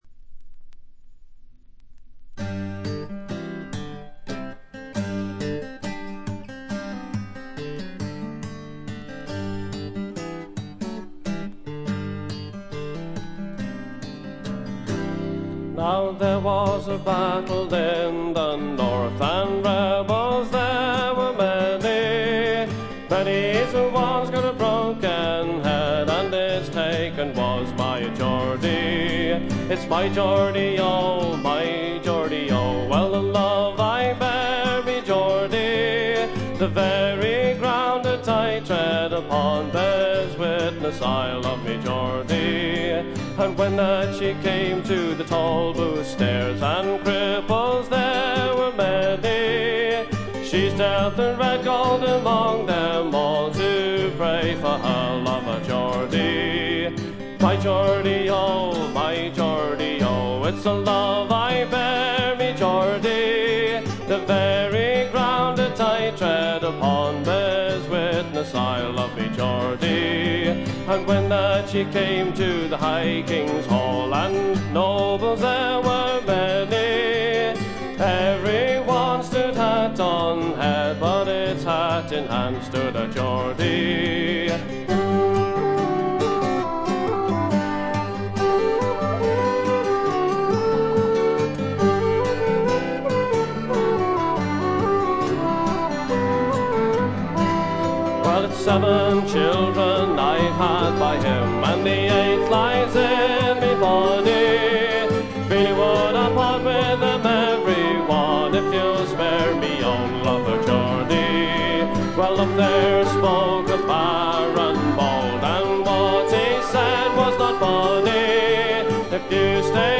ごくわずかなノイズ感のみ。
アコースティック楽器のみで、純度の高い美しい演奏を聴かせてくれる名作です。
試聴曲は現品からの取り込み音源です。